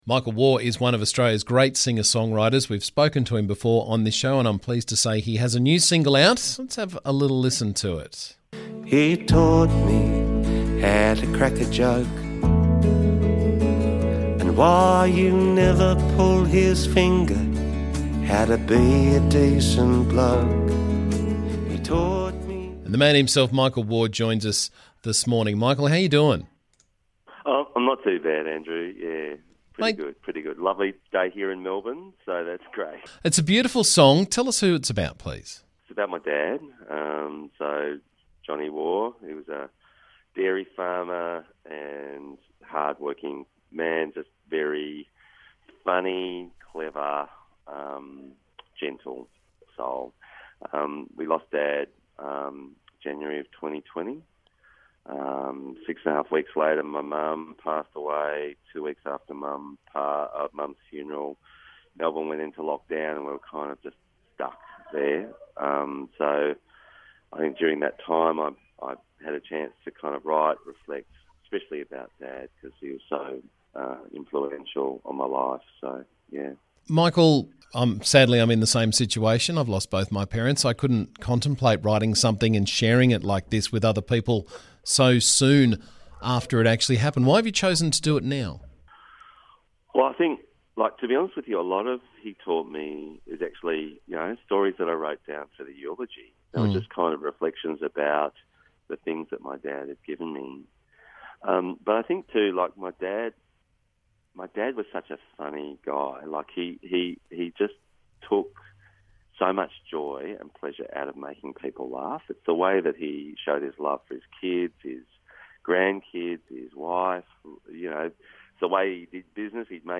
he was on the show to tell us about it this morning.